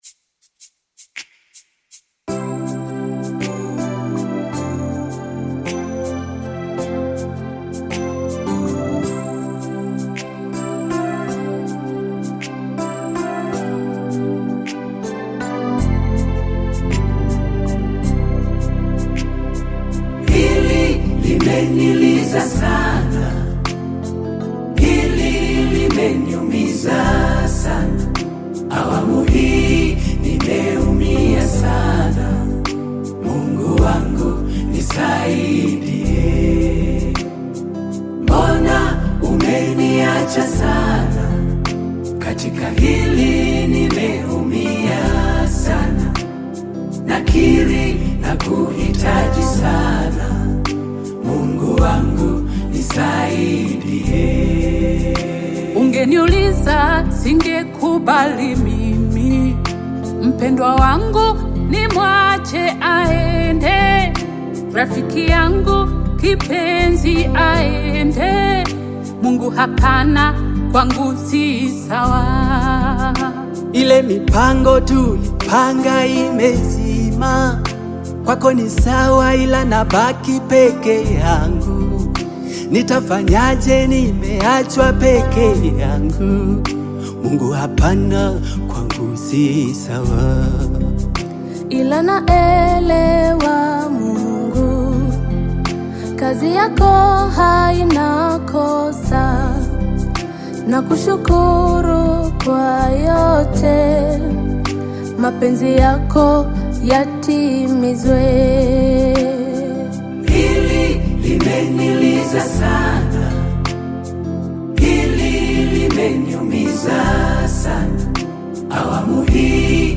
Gospel music track
Tanzanian gospel group